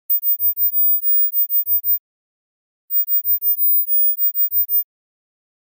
Nokia Funny Tone